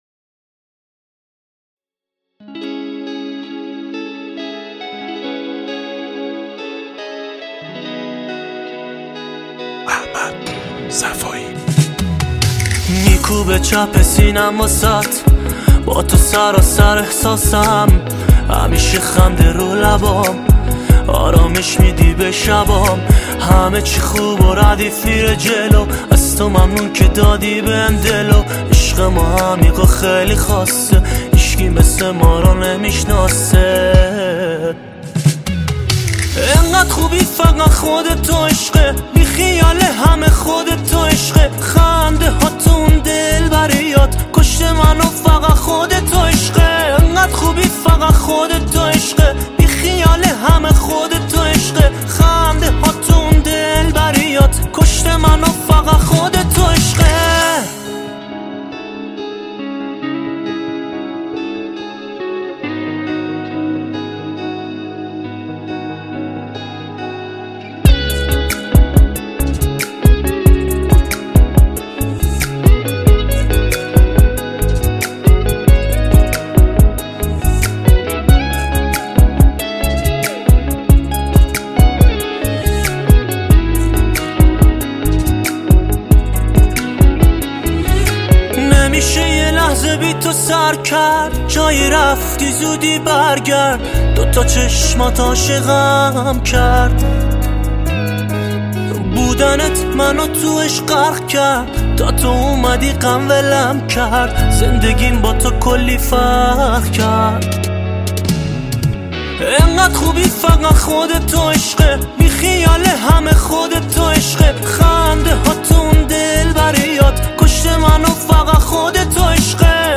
آهنگ عاشقانه